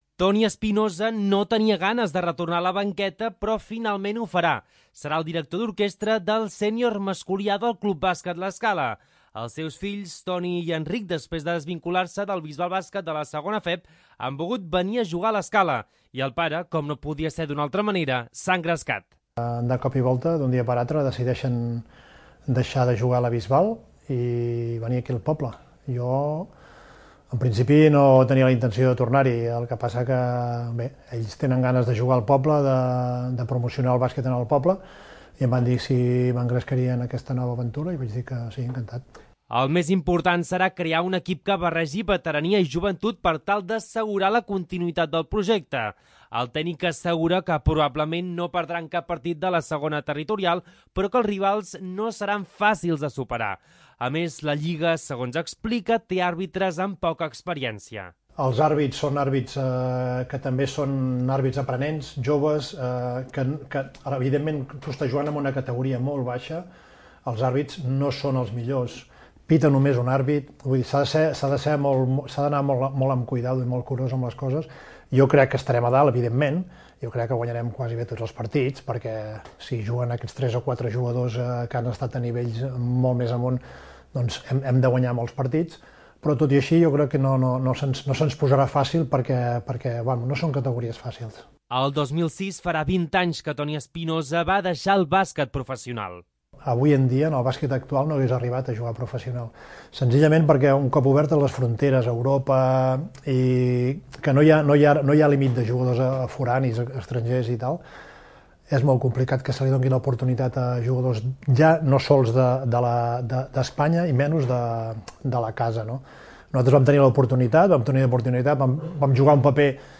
petits extractes de l'entrevista